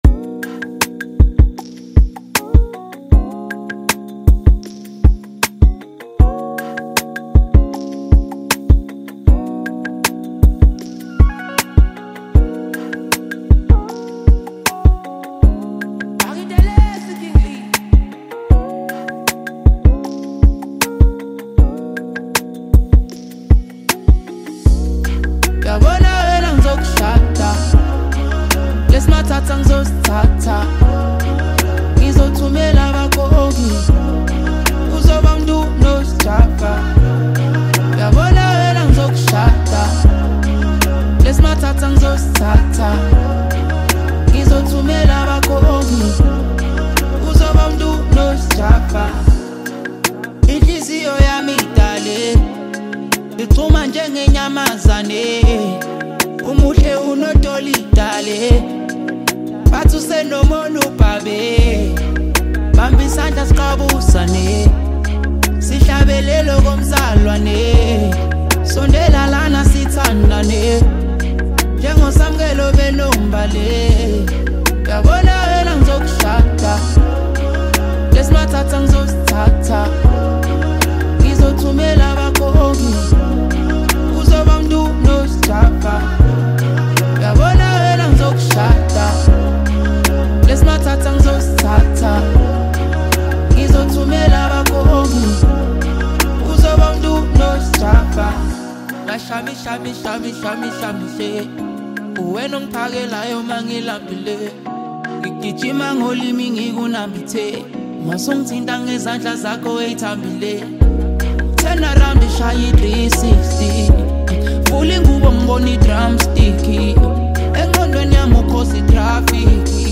Home » Amapiano » Hip Hop » Latest Mix